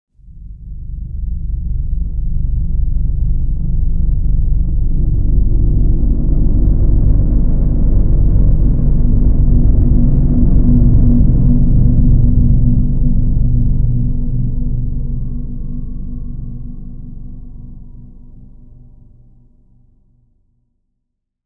دانلود آهنگ باد 73 از افکت صوتی طبیعت و محیط
دانلود صدای باد 73 از ساعد نیوز با لینک مستقیم و کیفیت بالا
جلوه های صوتی